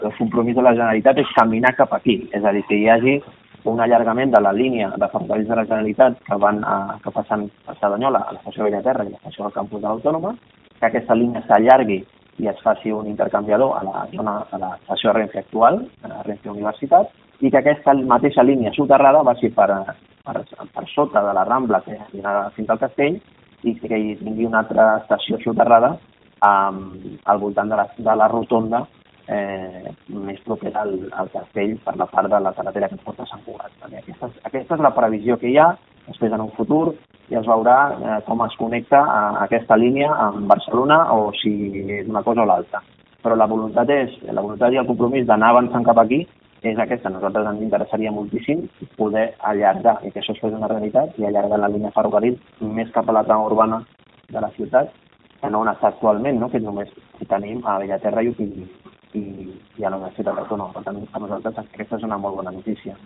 Declaracions de Carlos Cordon